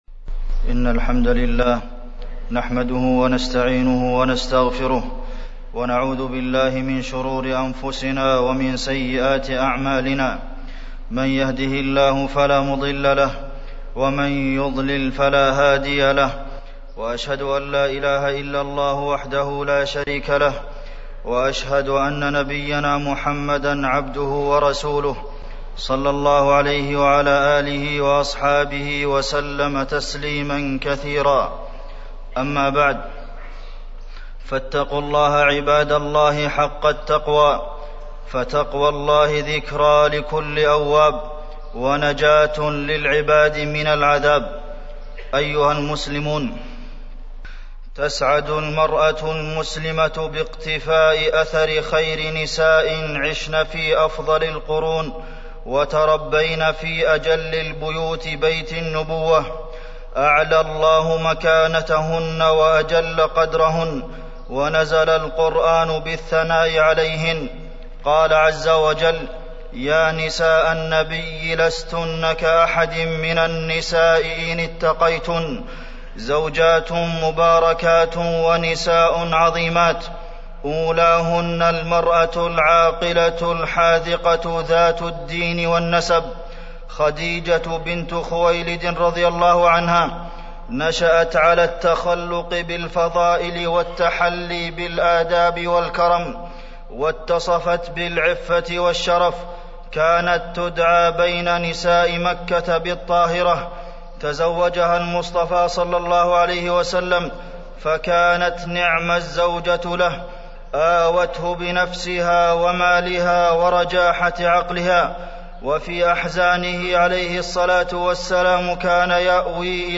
تاريخ النشر ٢٦ ربيع الثاني ١٤٢٦ هـ المكان: المسجد النبوي الشيخ: فضيلة الشيخ د. عبدالمحسن بن محمد القاسم فضيلة الشيخ د. عبدالمحسن بن محمد القاسم أمهات المؤمنين